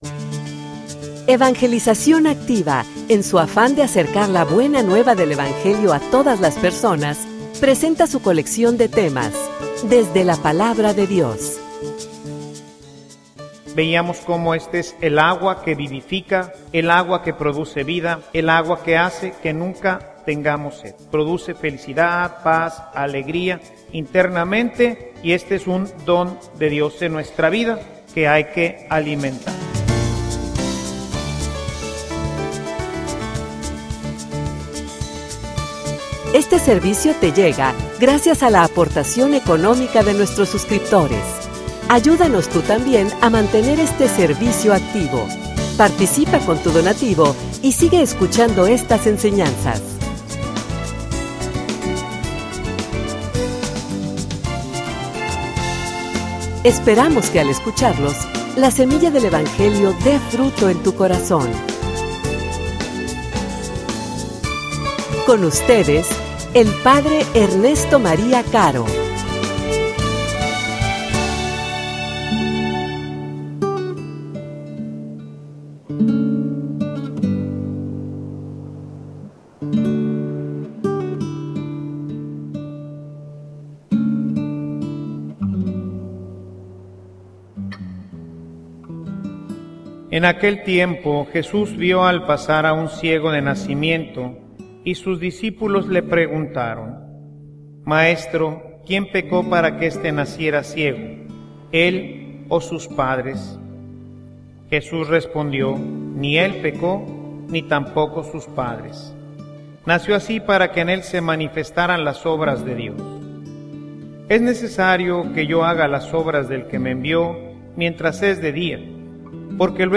homilia_La_obediencia_ilumina.mp3